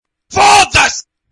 ruca fodasse 3 Meme Sound Effect
Category: Reactions Soundboard